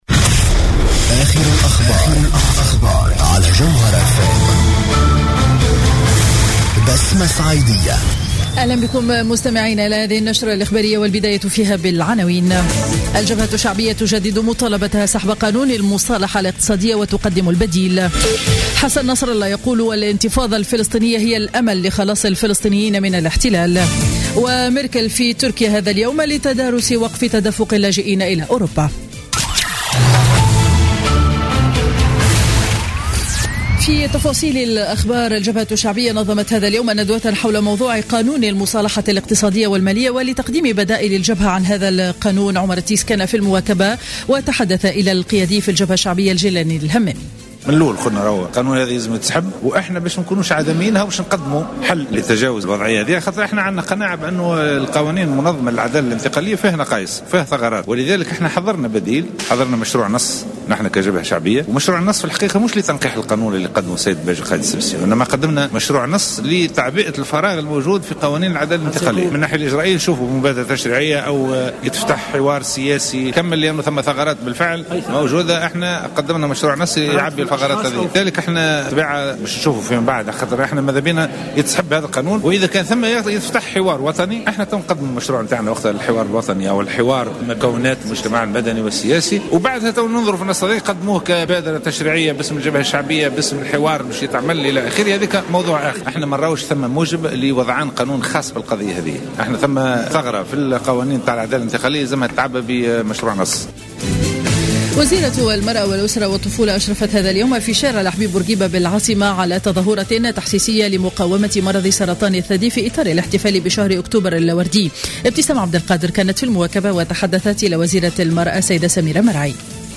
نشرة أخبار منتصف نهار يوم الأحد 18 أكتوبر 2015